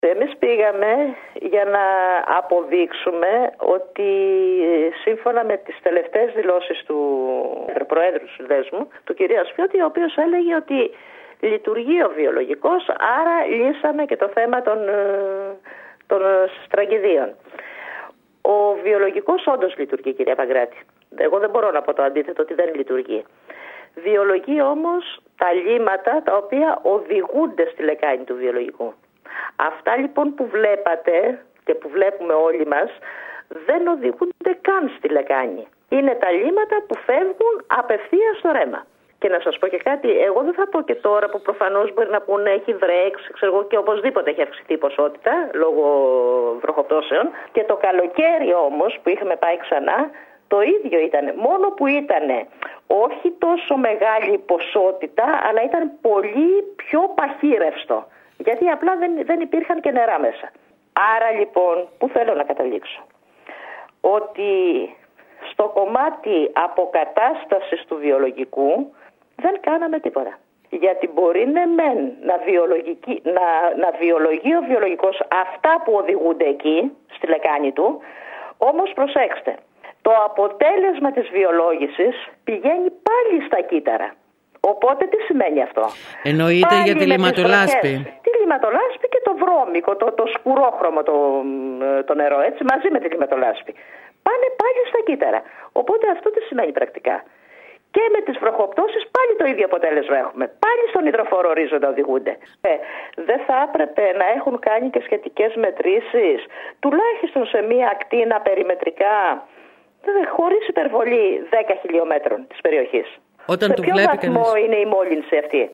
μιλώντας σήμερα στην ΕΡΑ ΚΕΡΚΥΡΑΣ